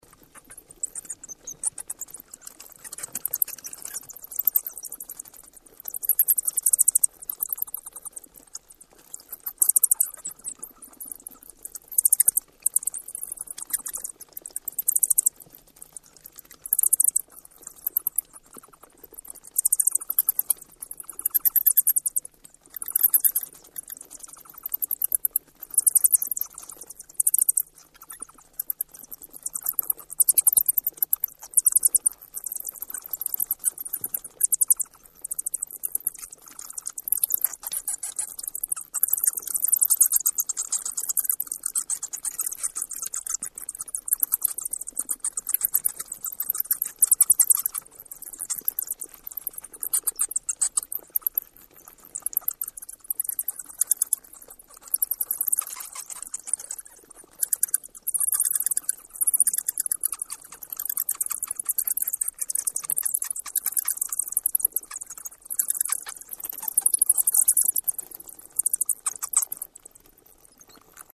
На этой странице собраны звуки, которые издают крысы: от тихого писка до активного шуршания.
Звуки домашних крыс